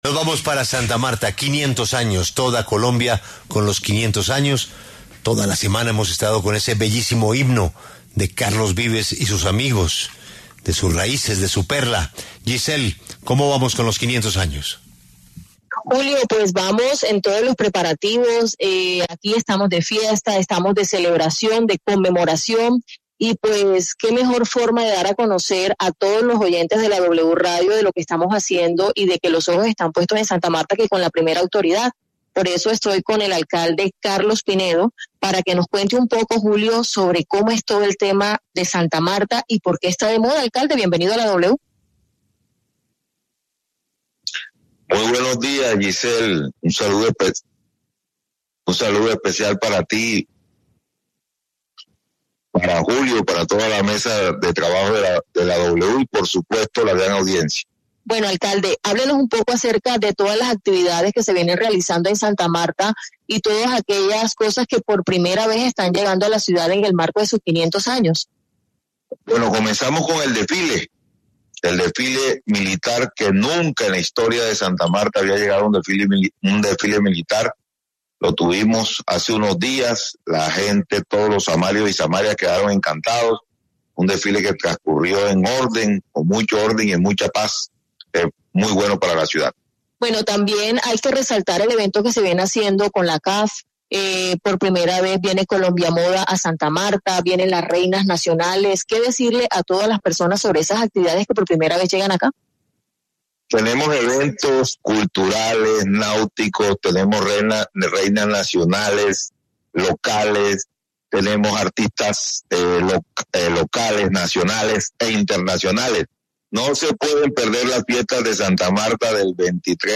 El alcalde de la ciudad Carlos Pinedo Cuello, habló en la W de toda la programación prevista del 23 al 29 de julio.